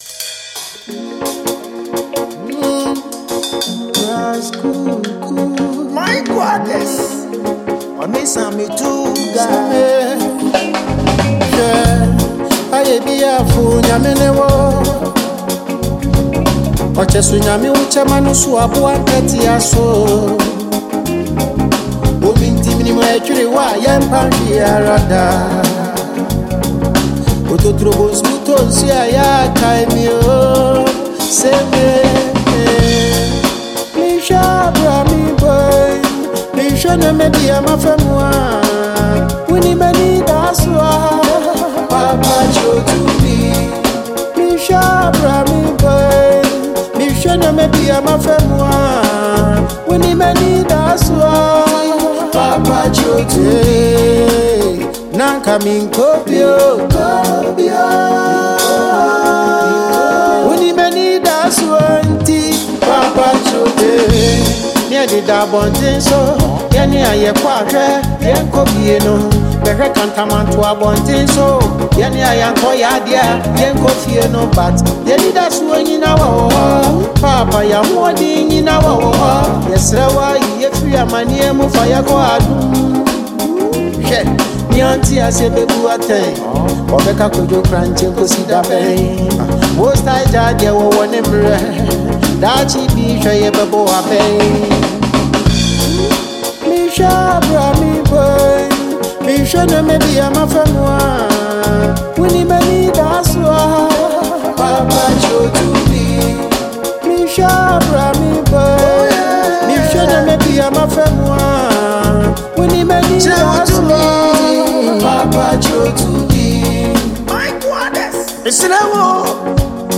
Award-winning Ghanaian reggae-dancehall musician